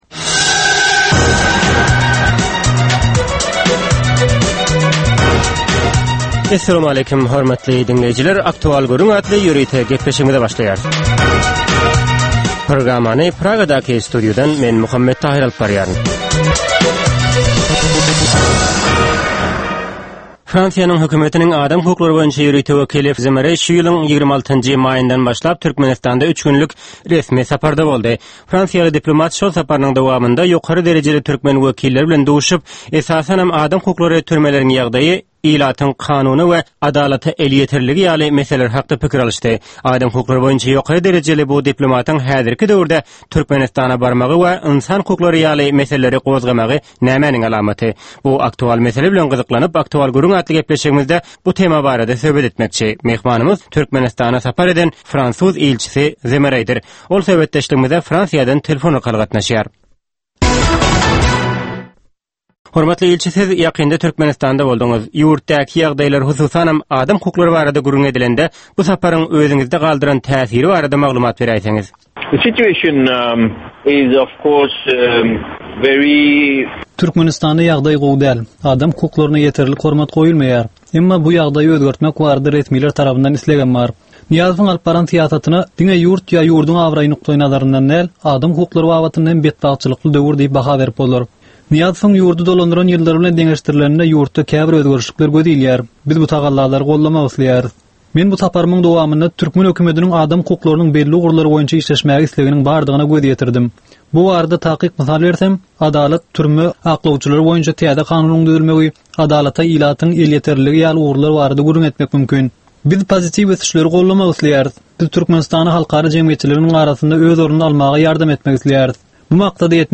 Hepdäniň dowamynda Türkmenistanda ýa-da halkara arenasynda ýüze çykan, bolup geçen möhüm wakalar, meseleler barada anyk bir bilermen ýa-da synçy bilen geçirilýän ýörite söhbetdeşlik. Bu söhbetdeşlikde anyk bir waka ýa-da mesele barada synçy ýa-da bilermen bilen gürrüňdeşlik geçirilýär we meseläniň dürli ugurlary barada pikir alyşylýar.